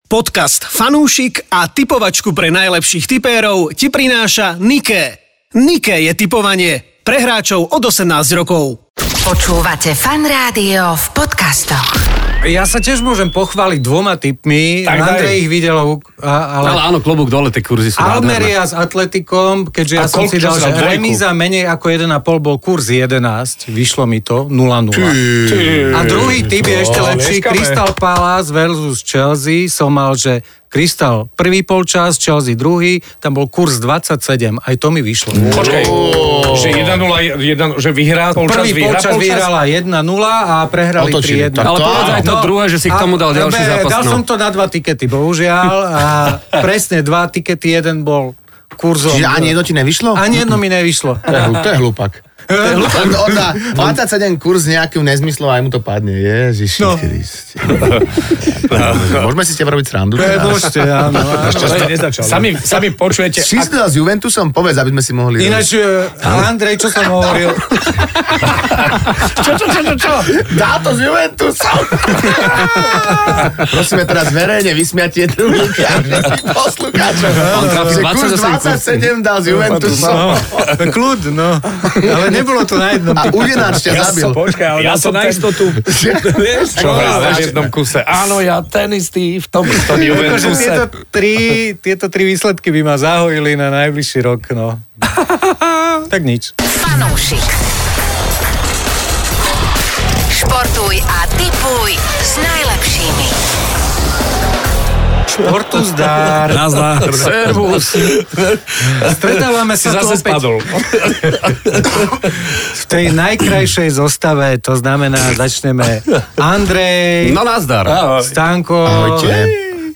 Daj si fanúšikovskú debatku o športe a tipovaní.